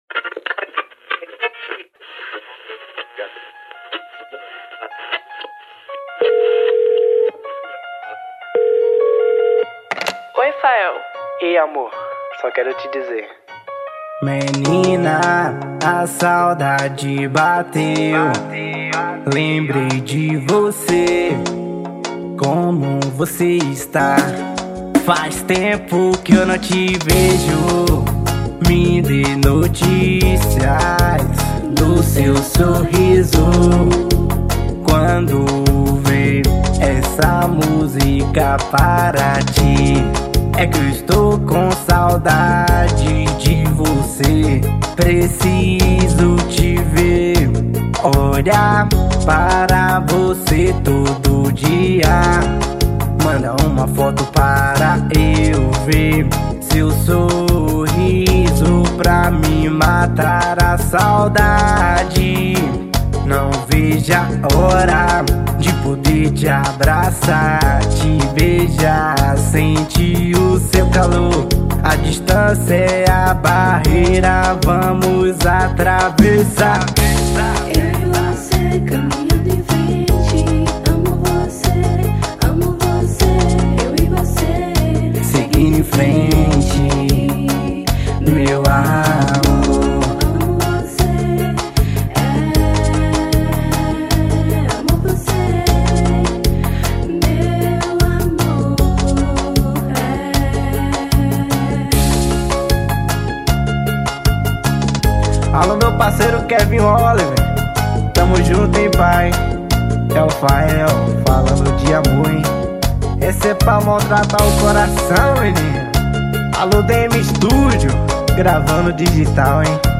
EstiloSwingueira